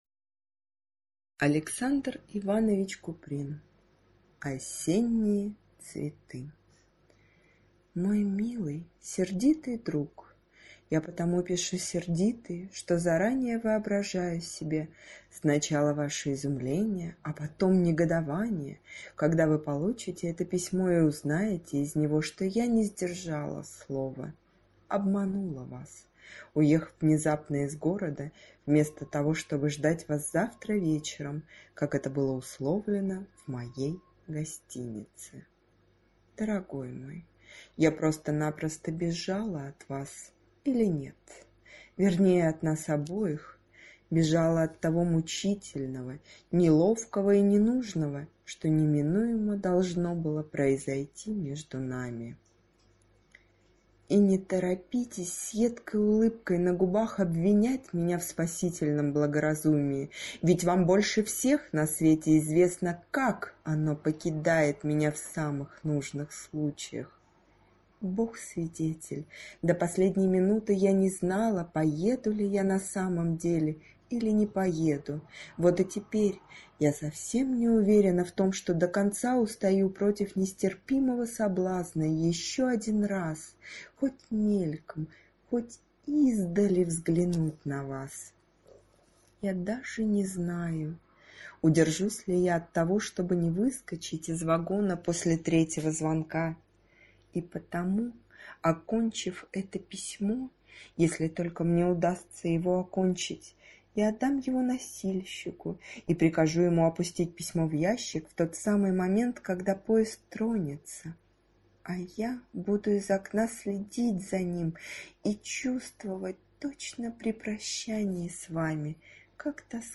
Аудиокнига Осенние цветы | Библиотека аудиокниг
Прослушать и бесплатно скачать фрагмент аудиокниги